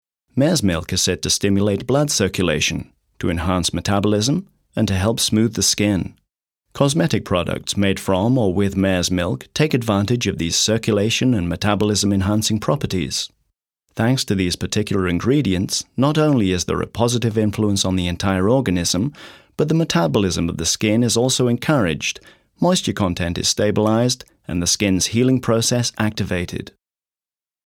Baujahr 1968: Eine jugendhafte, klare, ausdrucksvolle Stimme.
His German pronunciation is excellent with a hint of a British accent.
Sprecher britisch - englisch.
Sprechprobe: Industrie (Muttersprache):